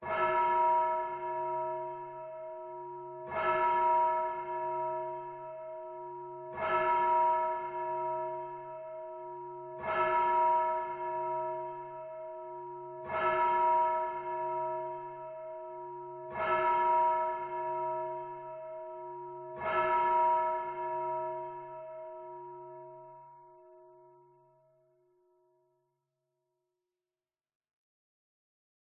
Звуки церковных колоколов
Звон далекого храмового колокола, бьющий семь раз